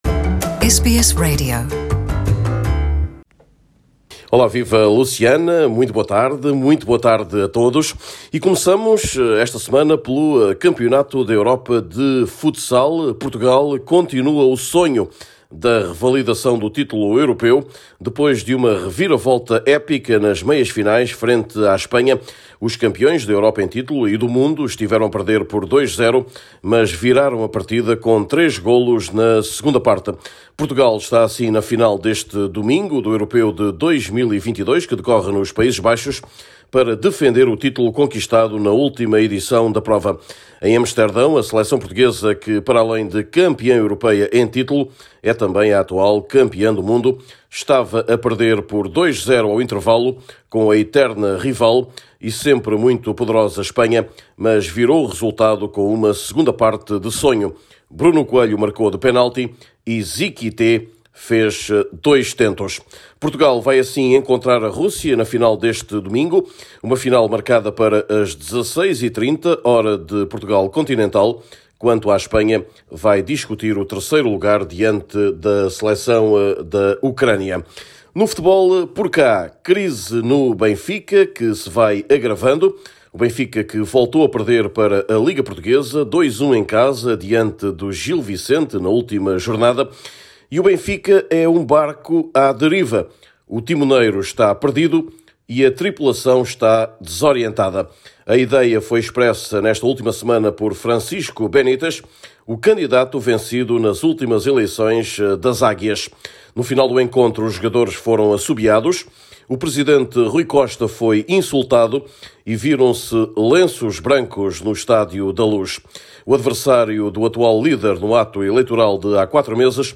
Neste boletim: Equipa das quinas eliminou a Espanha nas meias-finais e vai tentar revalidar o título este domingo, contra a Rússia. O fecho do mercado de Janeiro, que trouxe reforços para Sporting e FC Porto, mas apenas confirmou a crise desportiva do Benfica.